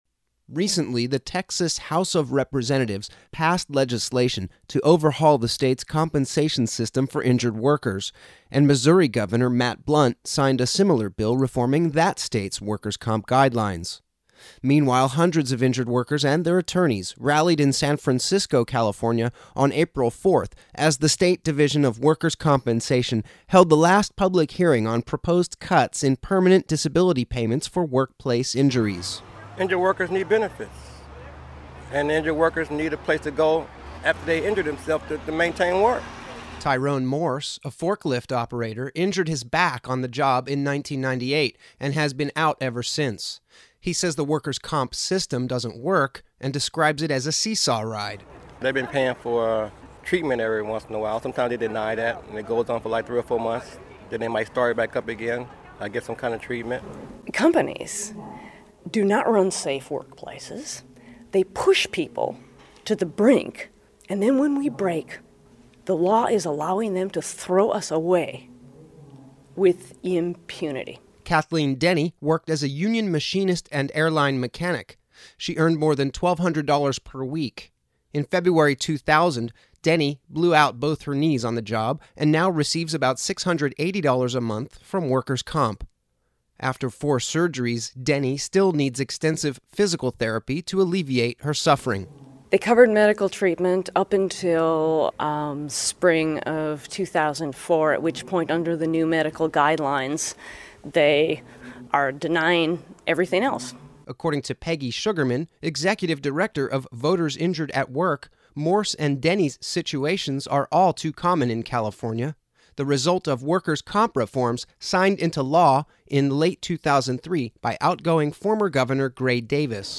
Injured workers rallied in San Francisco, April 4, as the state Division of Workers' Compensation, held the last public hearing on proposed cuts in permanent disability payments for workplace injuries.